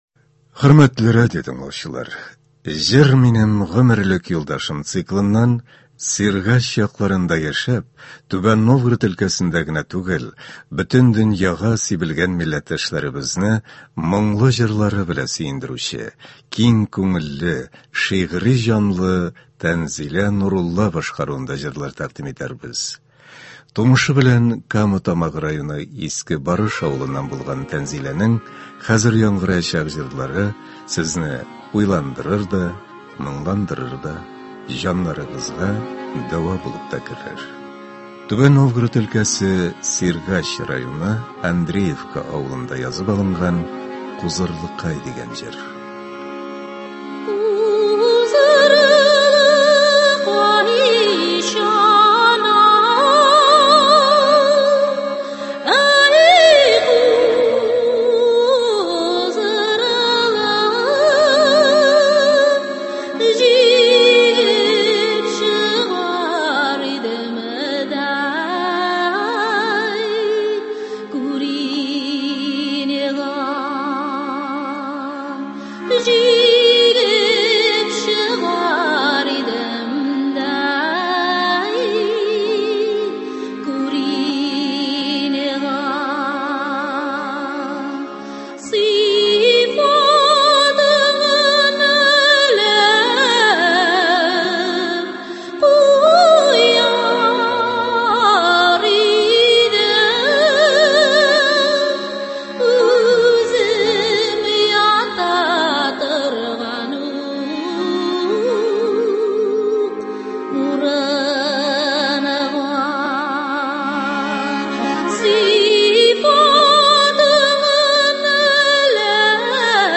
Концерт. 13 апрель.